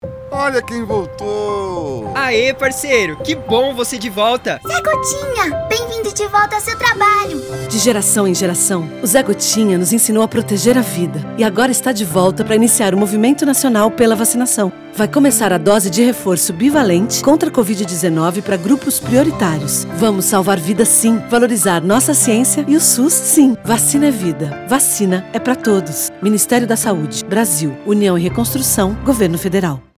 Spot - Vacinação Contra a Covid-19 - mp3